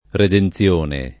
redenzione [ reden ZL1 ne ] s. f.